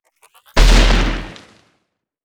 Grenade8.wav